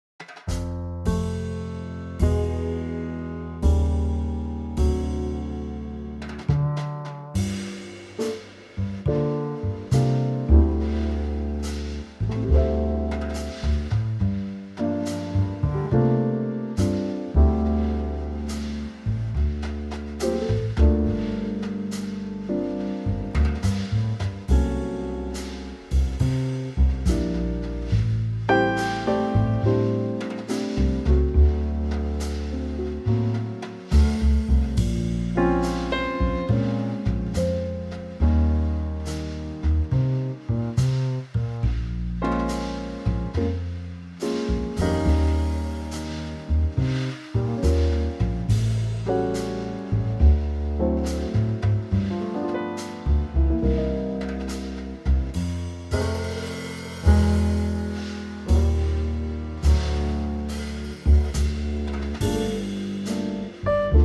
key F
key - F - vocal range - C to Eb
Gorgeous Trio ballad arrangement